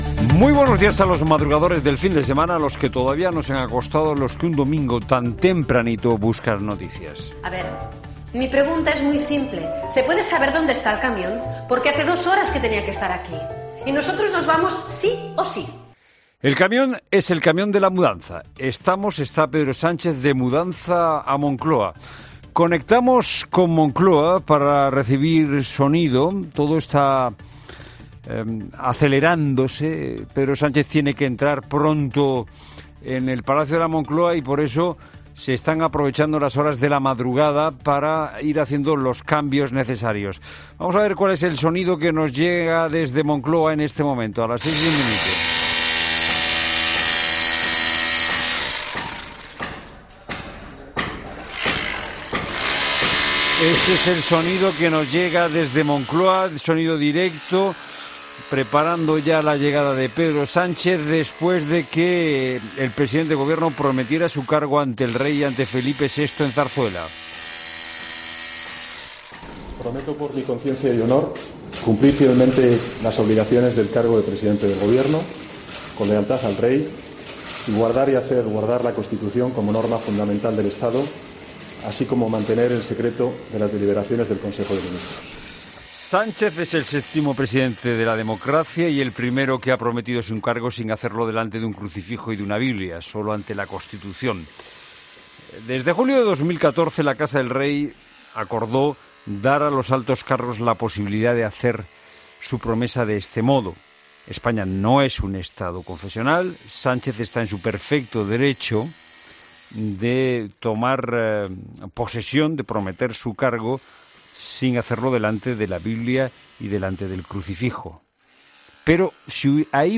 Conectamos con Moncloa para recibir sonido directo, todo tiene que estar listo pronto y se están aprovechando estas horas de la madrugada para...
Es sonido en directo desde Moncloa después de que ayer Pedro Sánchez prometiera su cargo ante el Rey y ante Felipe VI en Zarzuela.